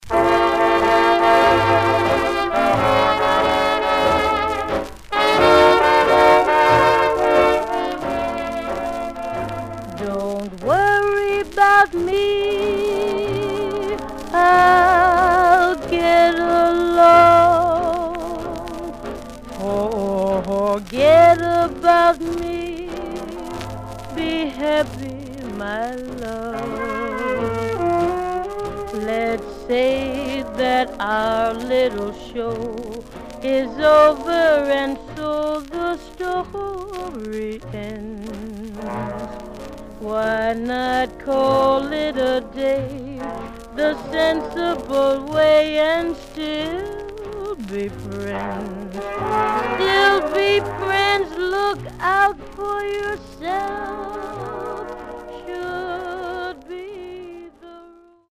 Some surface noise/wear
Mono
Jazz